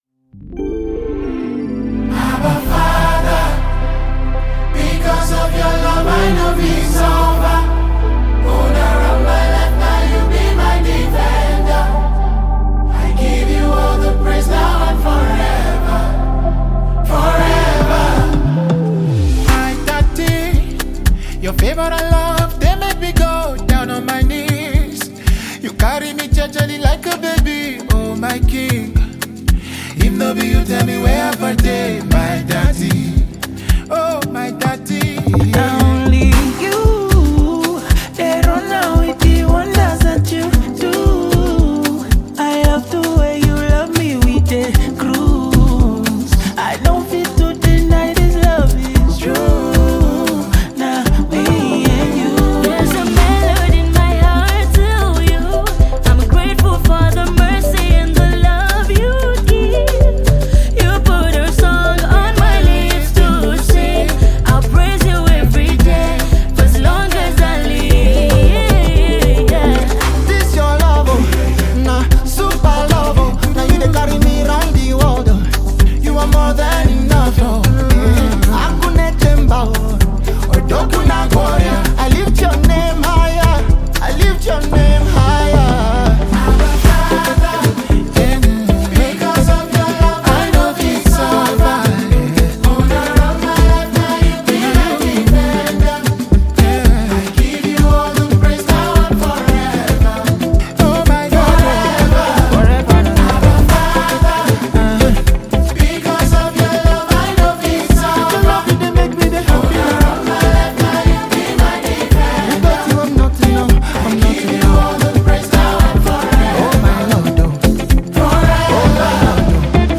Nigerian gospel artist